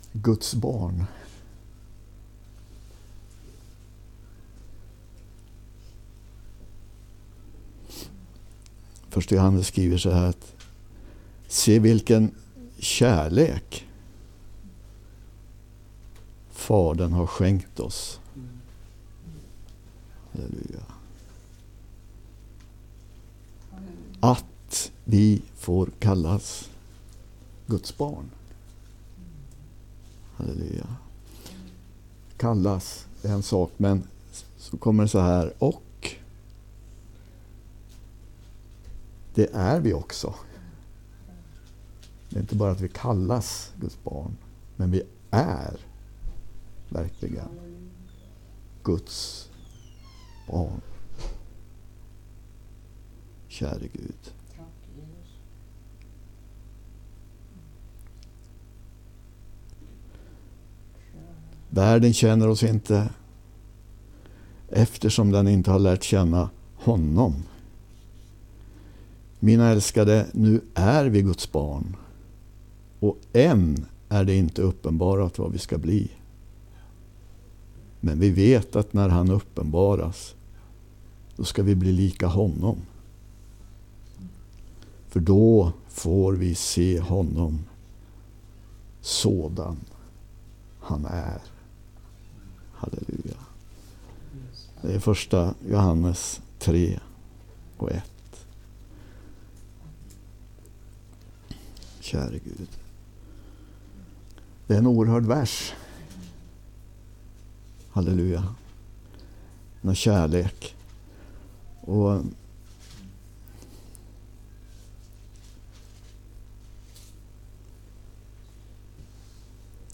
Undervisning inspelad hos församlingen i Skälby, Järfälla den 10 maj 2025